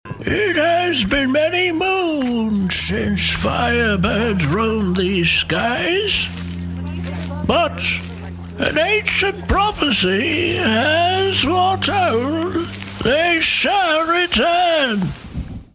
Audio Book Voice Over Narrators
Adult (30-50) | Older Sound (50+)